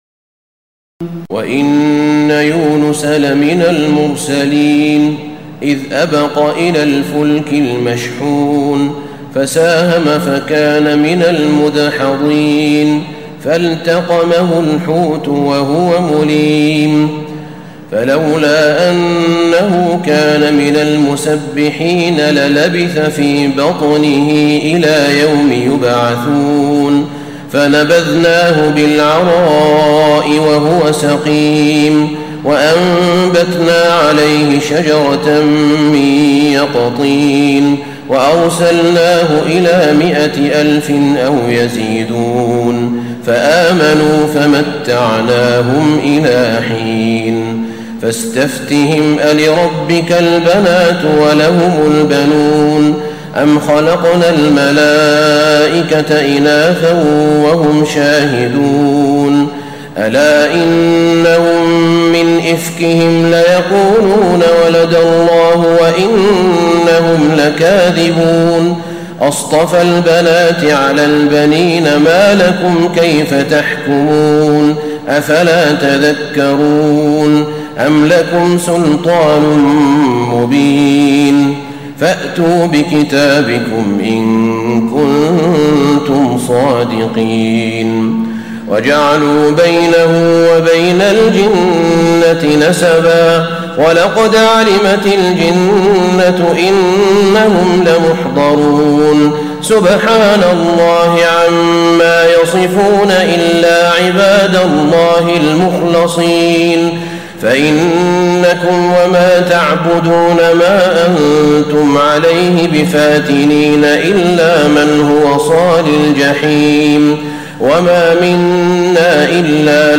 تراويح ليلة 22 رمضان 1436هـ من سور الصافات (139-182) وص و الزمر (1-31) Taraweeh 22 st night Ramadan 1436H from Surah As-Saaffaat and Saad and Az-Zumar > تراويح الحرم النبوي عام 1436 🕌 > التراويح - تلاوات الحرمين